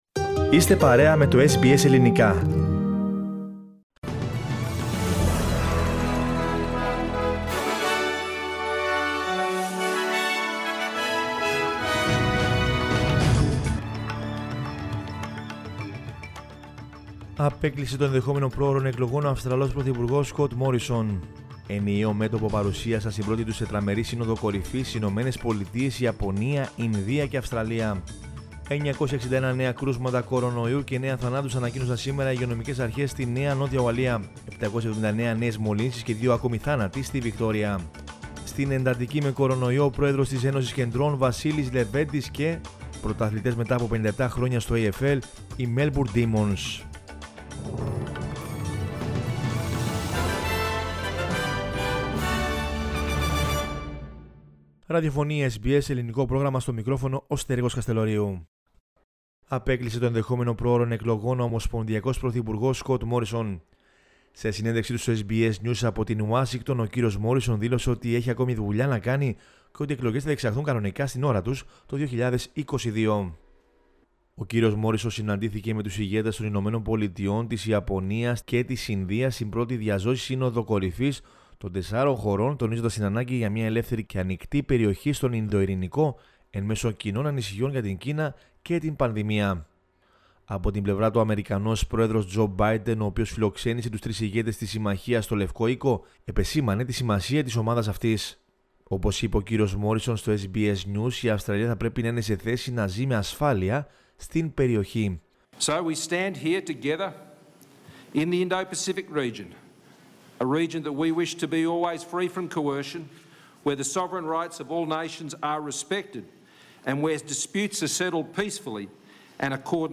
News in Greek from Australia, Greece, Cyprus and the world is the news bulletin of Sunday 26 September 2021.